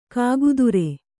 ♪ kāgudure